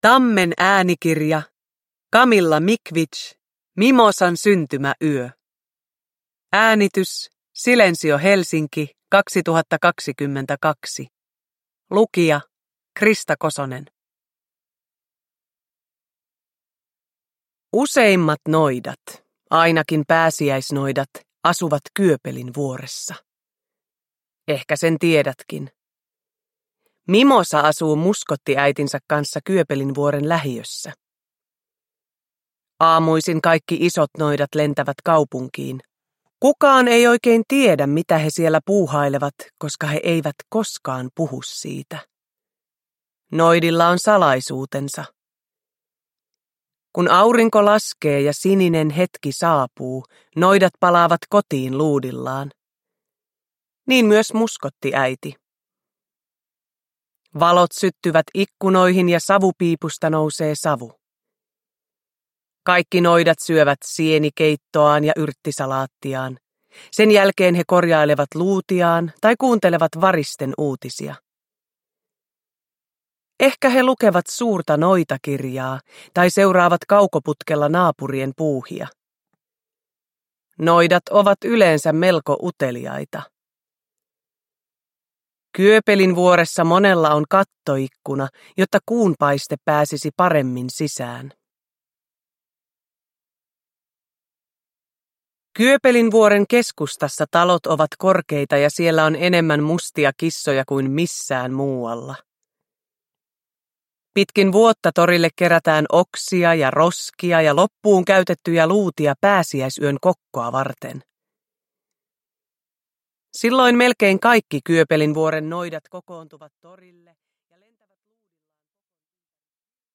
Mimosan syntymäyö – Ljudbok – Laddas ner
Uppläsare: Krista Kosonen